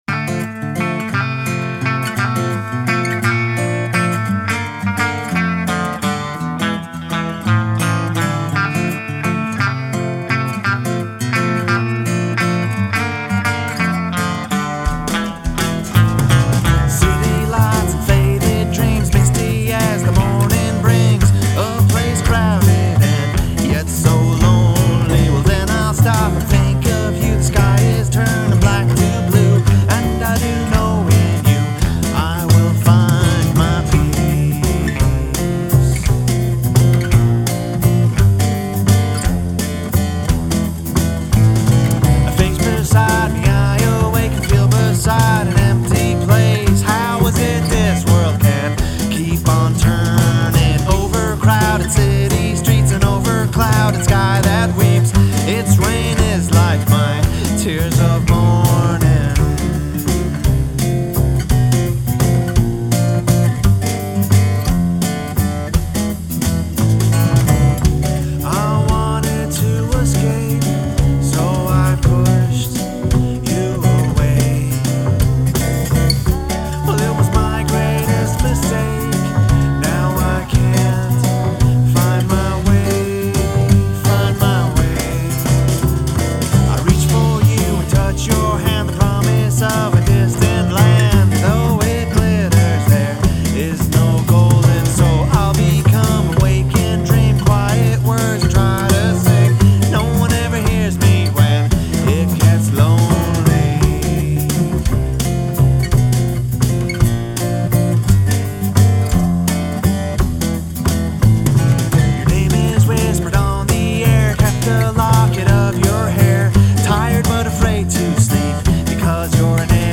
bass
drums
backing vocals